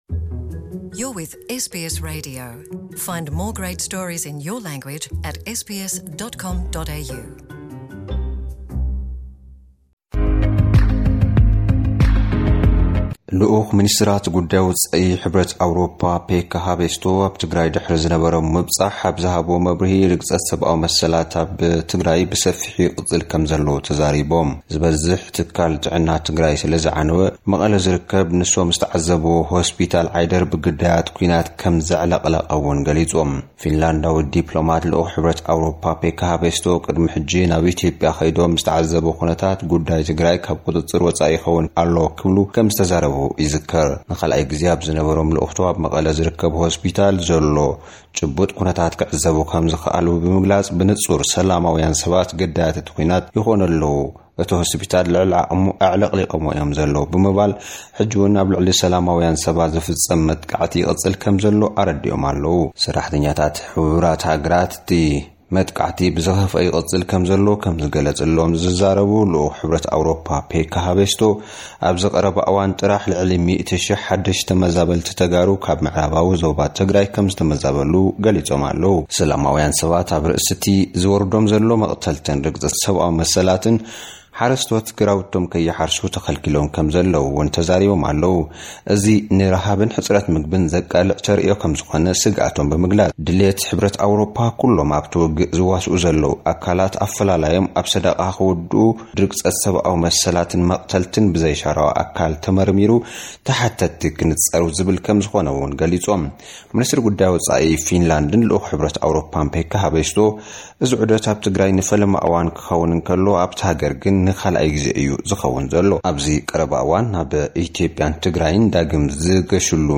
ኣርእስታት ዜና፡